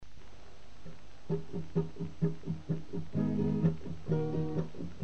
Акустика
вокал, гитара
перкуссия
альт